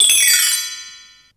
BELLTREEEE-R.wav